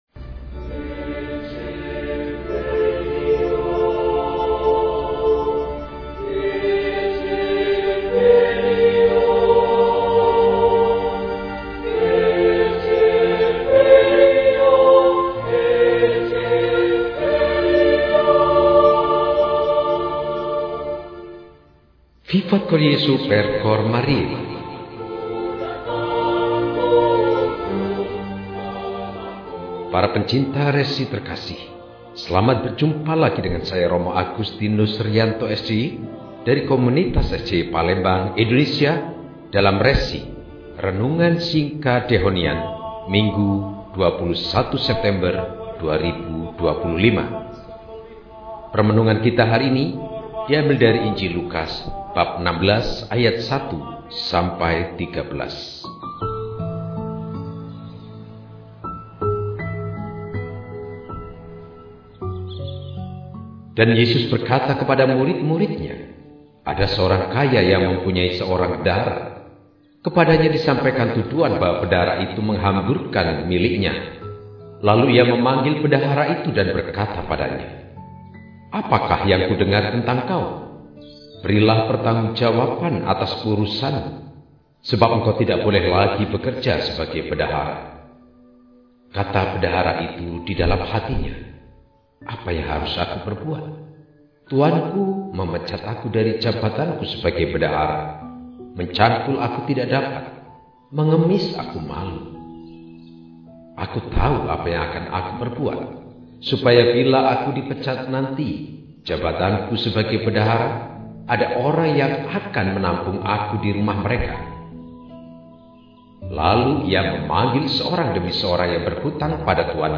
Minggu, 21 September 2025 – Hari Minggu Biasa XXV – RESI (Renungan Singkat) DEHONIAN